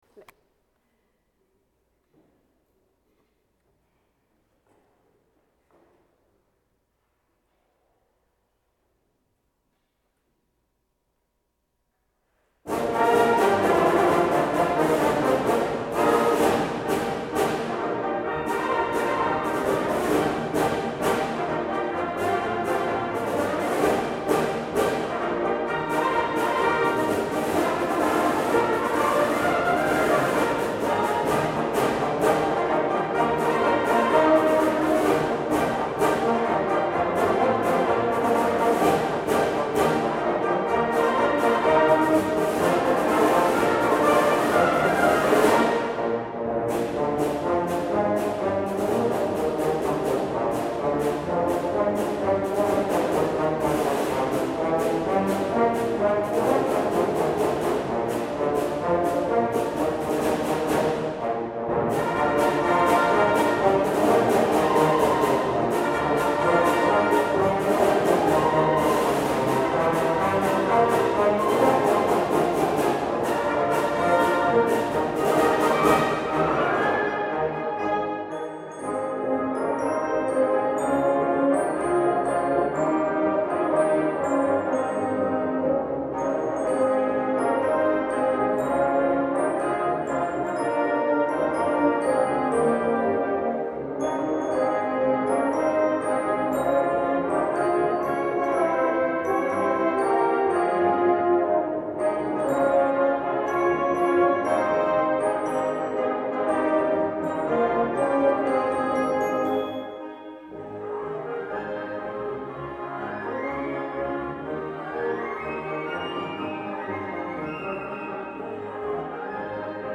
The young man playing first chair trumpet
I filled in on bass drum for the recording.
MARCH MUSIC; MILITARY MUSIC